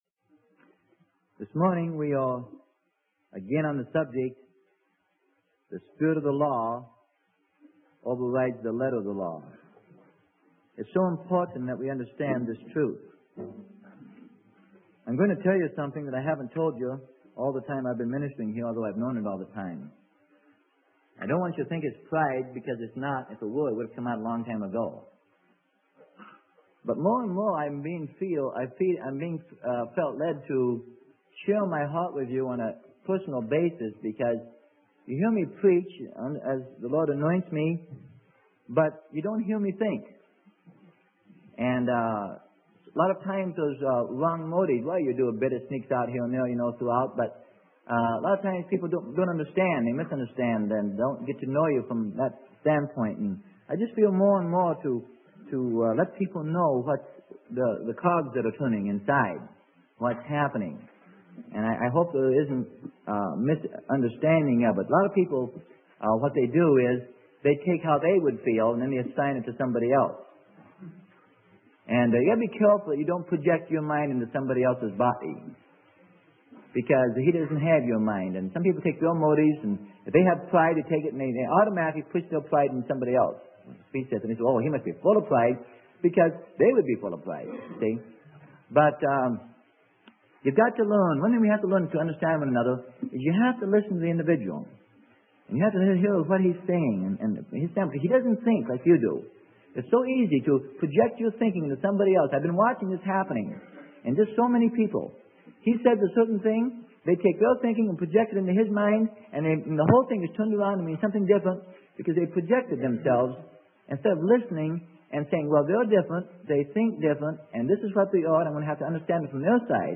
Sermon: Spirit vs Letter of the Law - Part 2 - Freely Given Online Library